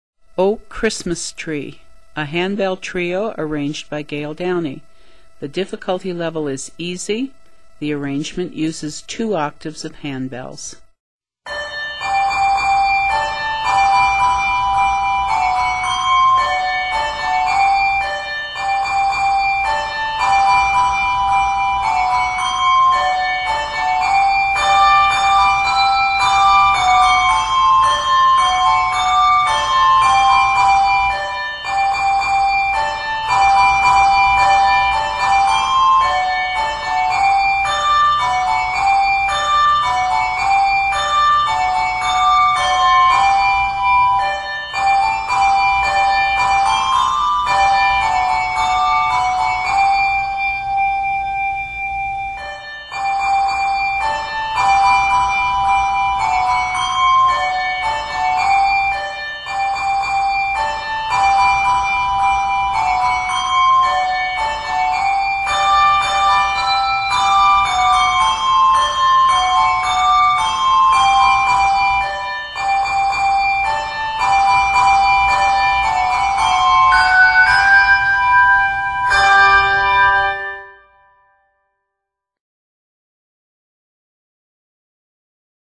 The rhythms are simple and the mood is light and joyous.